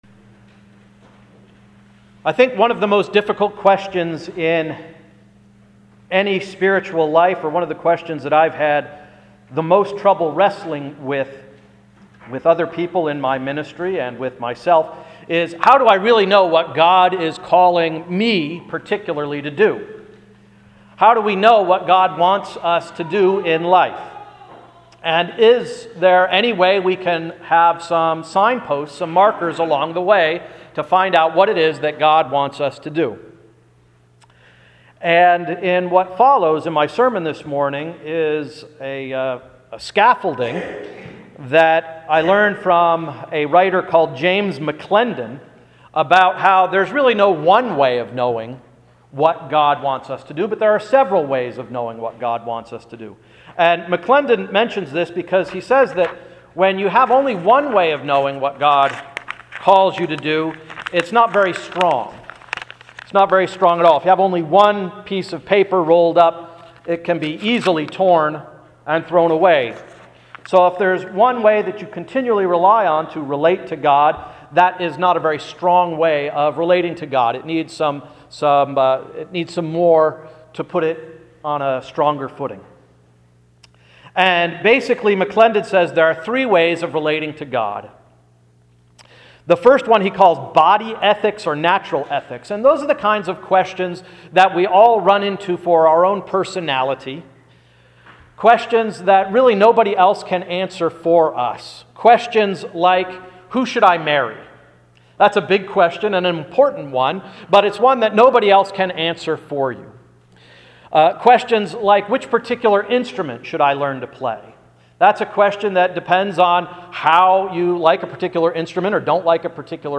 Sermon of January 13, 2013–“Somebody’s Calling My Name”